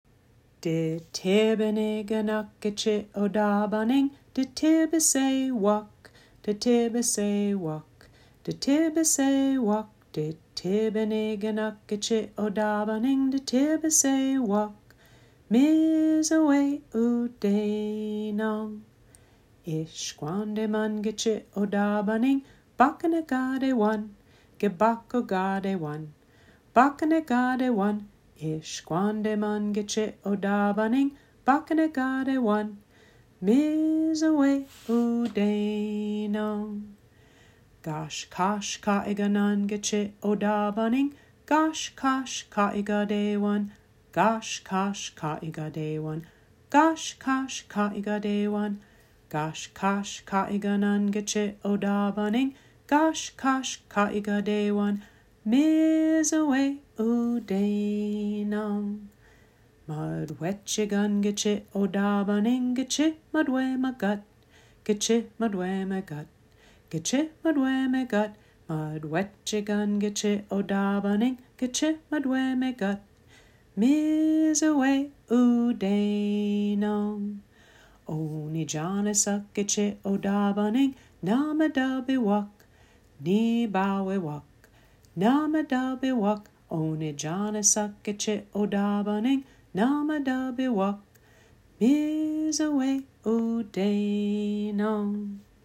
Children’s Songs
The Ojibwe version here is sung by students who live in Gichi-Onigaming and Cook County, Minnesota. The audio is intentionally slow and focused on learning the names for what we see on the bus and using the words for what they do.